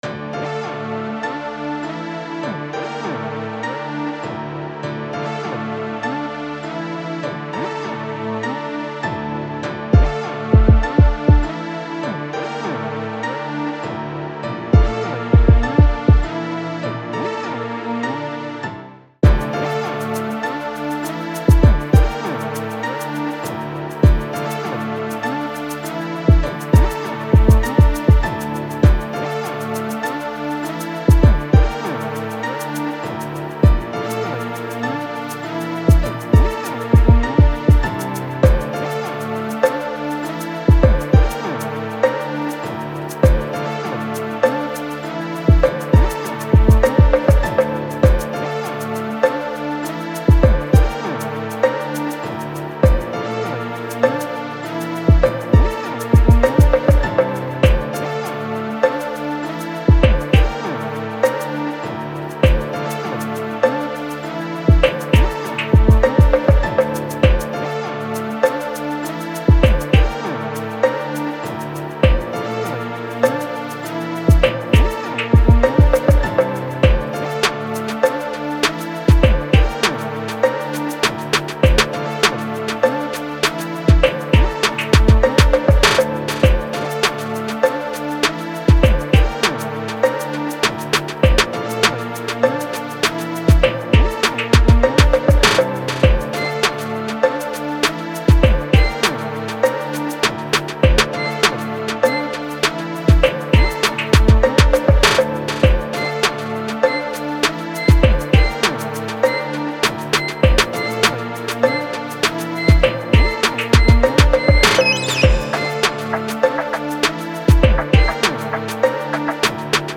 04:51 Genre : Trap Size